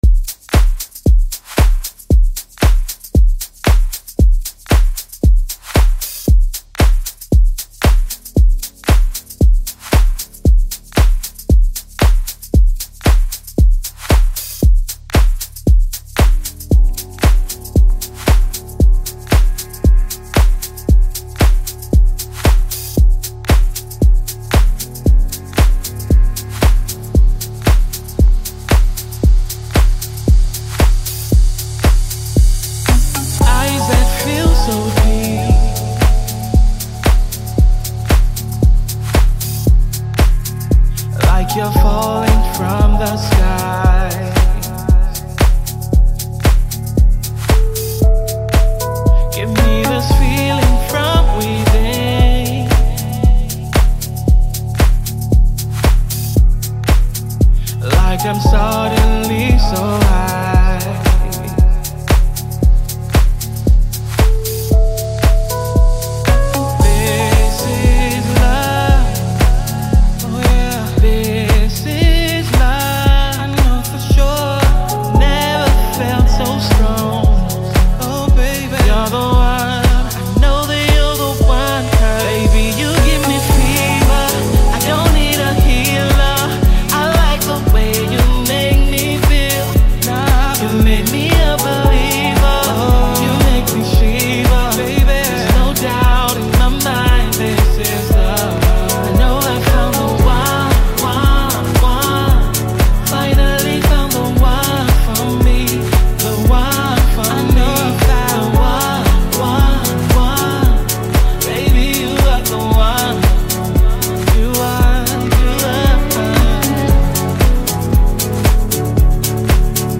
Home » Hip Hop » Amapiano » DJ Mix
South African singer-songsmith